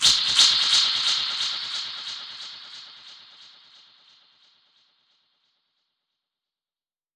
Index of /musicradar/dub-percussion-samples/134bpm
DPFX_PercHit_C_134-06.wav